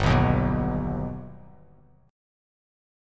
Listen to G+ strummed